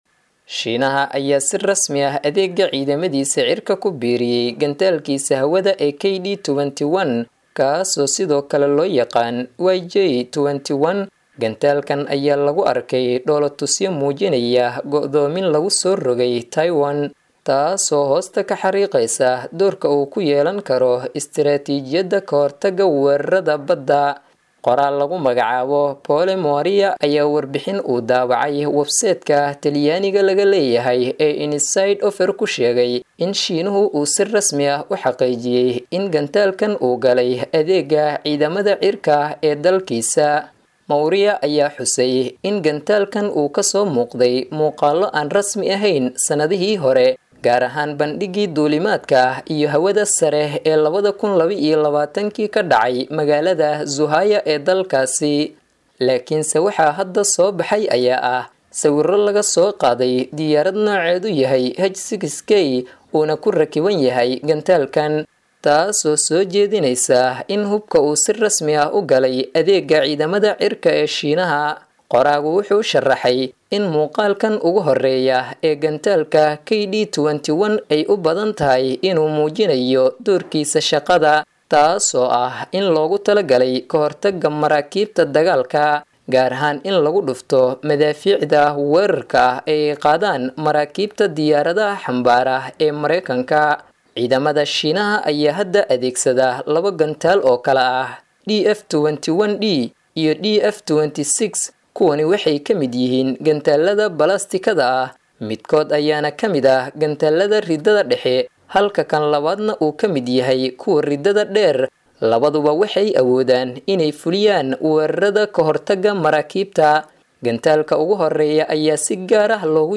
Shiinaha oo Soo Bandhigay Masaafada uu Gaarayo Hubkiisa Badda.[WARBIXIN]